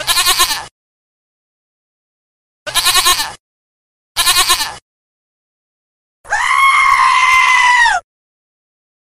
Ringetone Ged
Kategori Dyr
ged.mp3